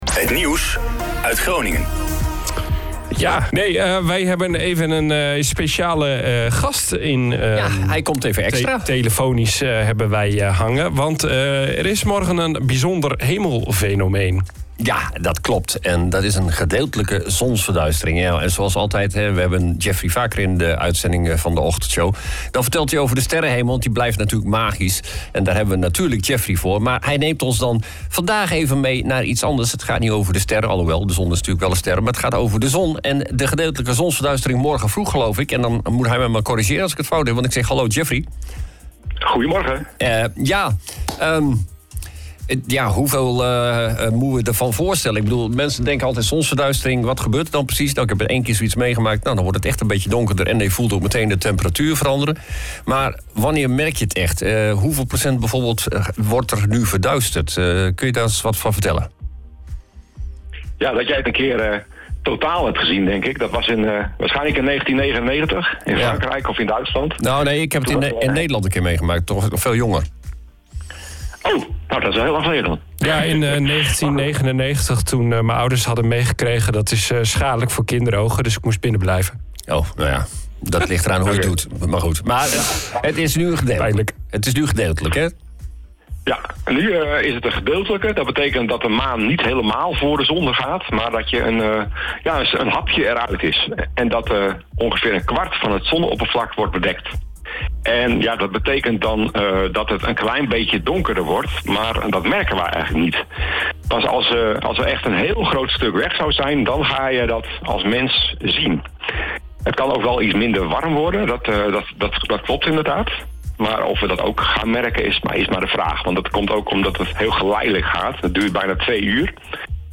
Zonsverduistering De OOG Ochtendshow is een vrolijke radioshow met het lokale nieuws, de beste muziek en natuurlijk het weer en beluister je iedere werkdag van 07:00 – 09:00 uur op OOG Radio. Hier vind je alle interviews van de OOG Ochtendshow.